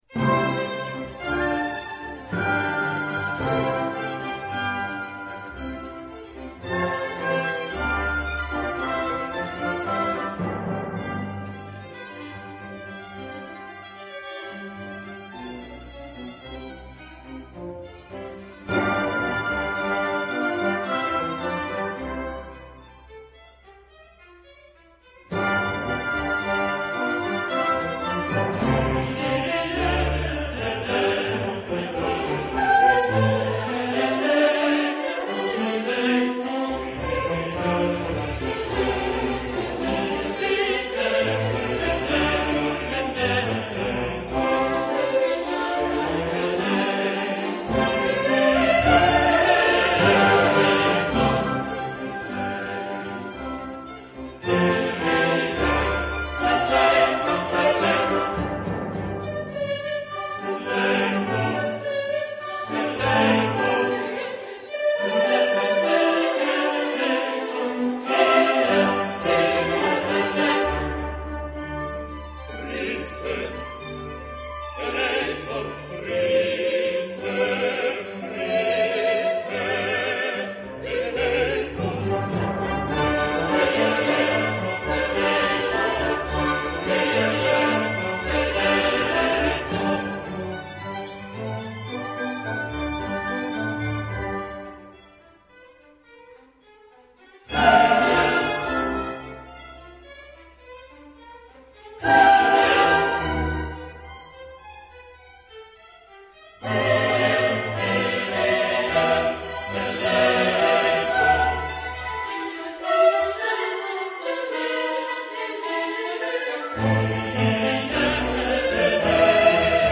Genre-Style-Forme : Sacré ; Messe ; Classique
Type de choeur : SATB  (4 voix mixtes )
Solistes : SATB  (4 soliste(s))
Instrumentation : Orchestre de chambre  (10 partie(s) instrumentale(s))
Instruments : Violon (2) ; Hautbois (2) ; Cor (2) ; Trompette (2) ; Basse (1) ; Orgue (1)
Tonalité : do majeur